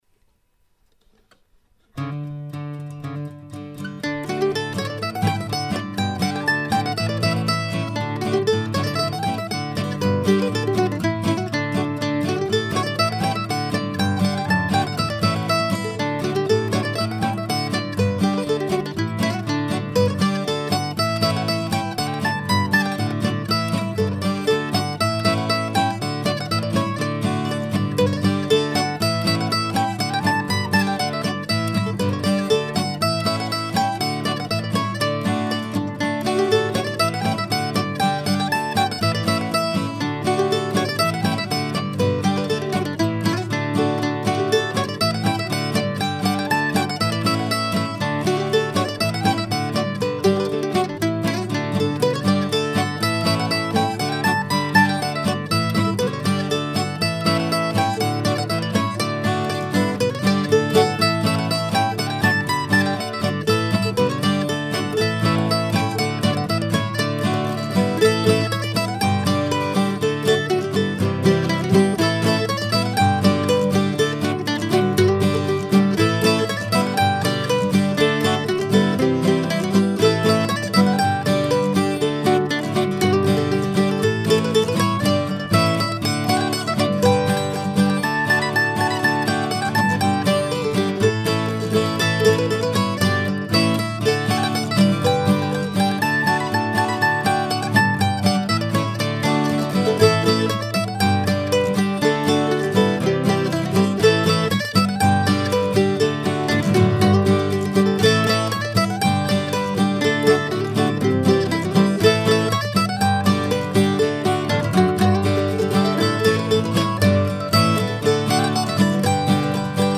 Jefferson Lives/Leadfoot/Go Figure ( mp3 )( pdf1 )( pdf2 ) Three reels that I recorded a couple of years ago as a demo for my Contratopia bandmates.
I mixed the recording in mono because, at the time, I was enjoying listening to the mono versions of the early Capitol Beatles albums. I still like the feel of this recording, plus I like the tunes.